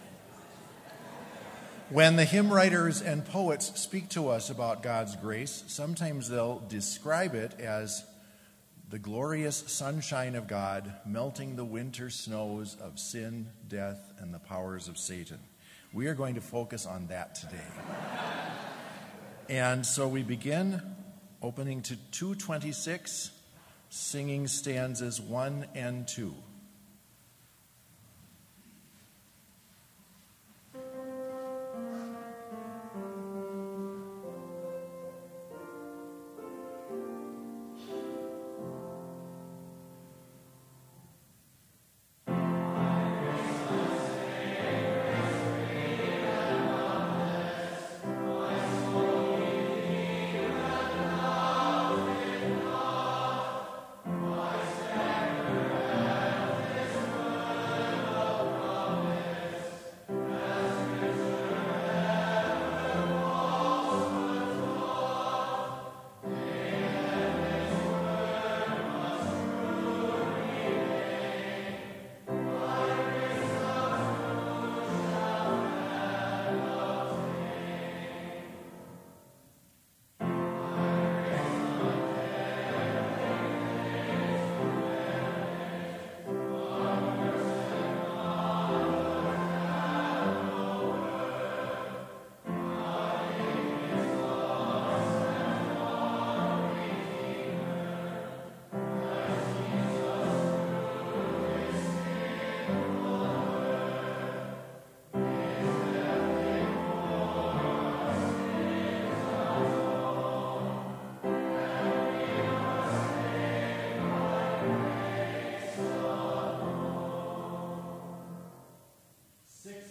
Complete service audio for Chapel - February 21, 2019